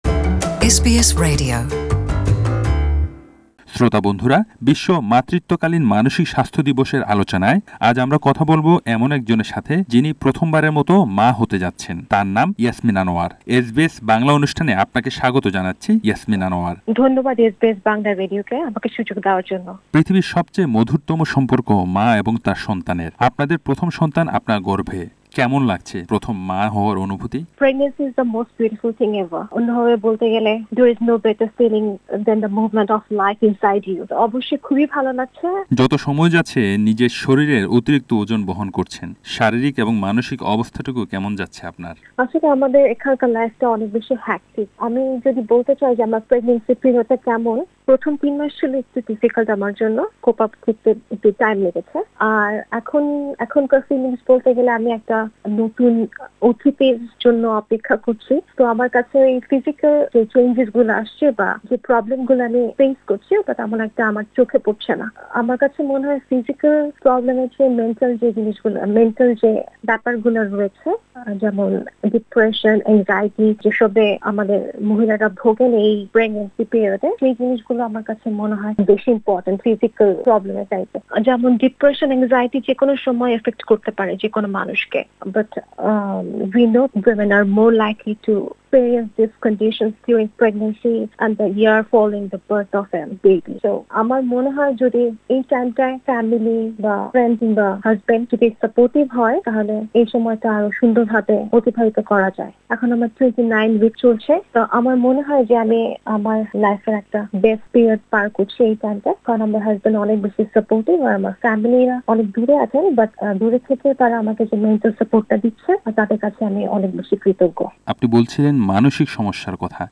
Many migrant women face increased difficulties during that time; they miss their families and often spending the day alone while their husbands are at work. SBS Bangla spoke with one pregnant migrant woman and mental health instructor.